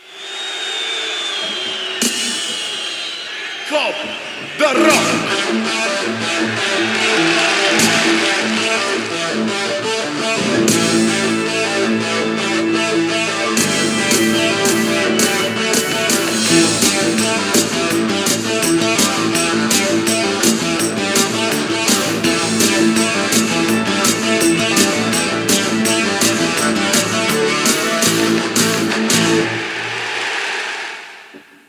Careta del programa
FM